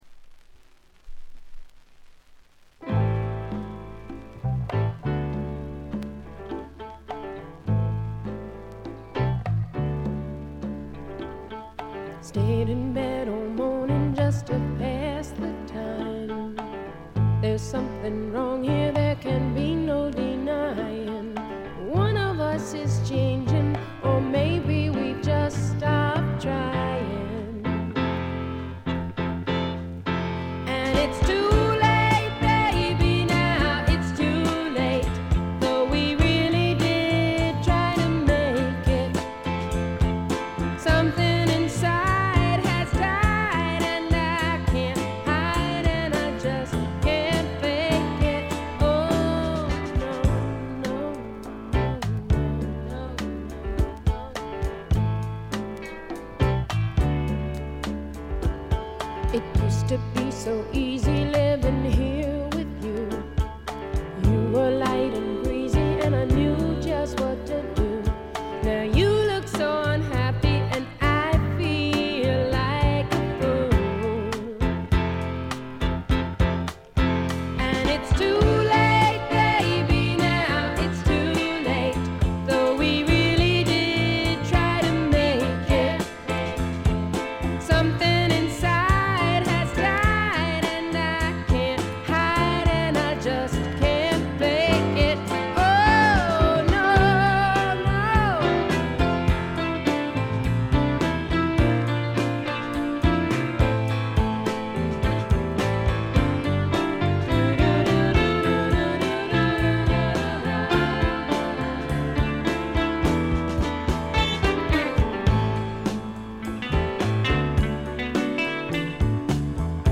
全体にバックグラウンドノイズ、チリプチ多め大きめ。
試聴曲は現品からの取り込み音源です。